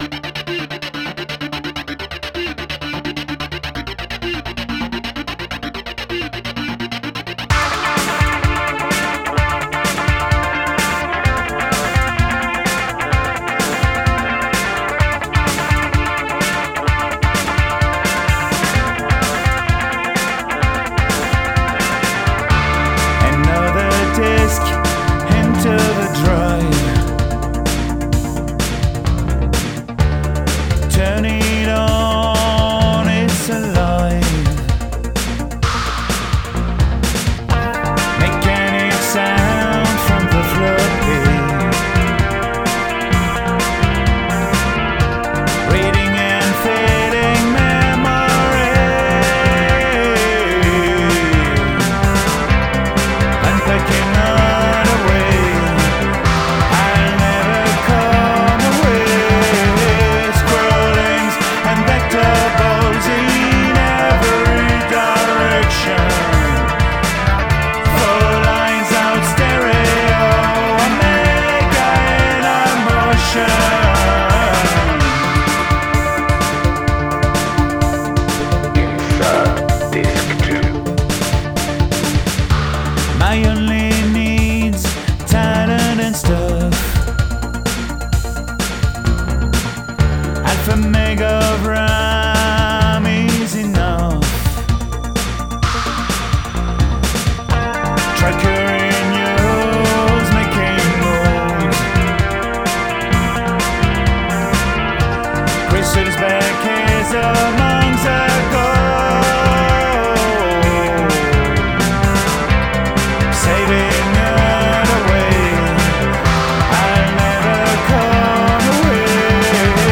DEMO
Homestudio RECORDING